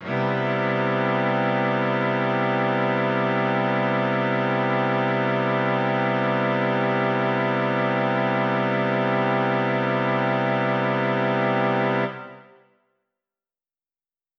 SO_KTron-Cello-Cmaj7.wav